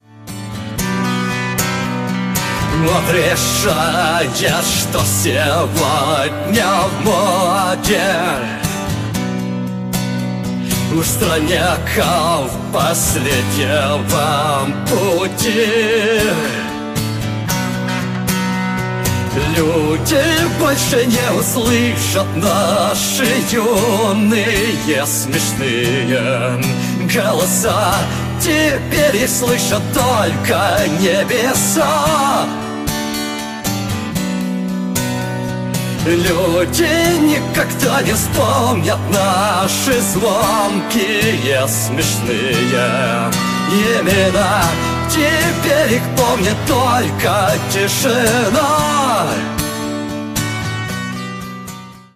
гитара
акустика
русский рок
Нейросеть